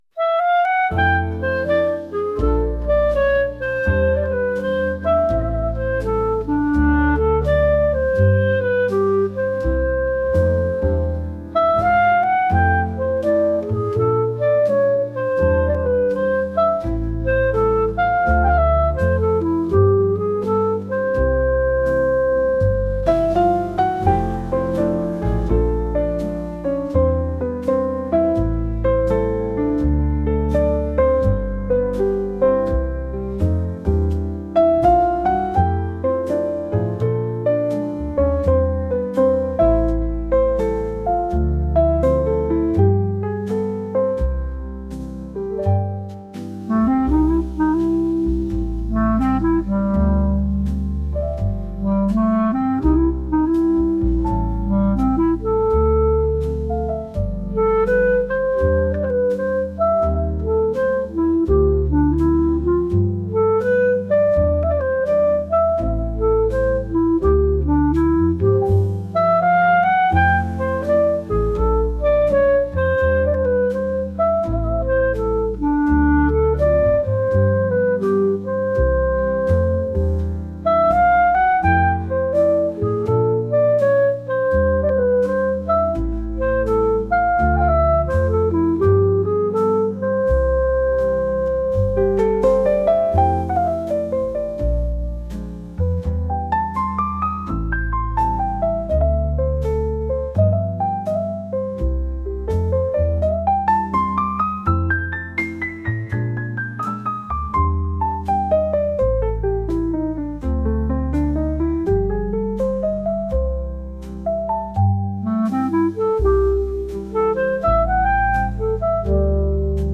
のんびりとした午後を楽しむクラリネット曲です。